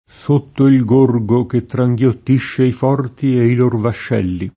S1tto il g1rgo ke ttrajgLott&šše i f0rti e i l1r vašš$lli] (D’Annunzio)